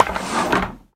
Minecraft Version Minecraft Version 25w18a Latest Release | Latest Snapshot 25w18a / assets / minecraft / sounds / ui / loom / take_result2.ogg Compare With Compare With Latest Release | Latest Snapshot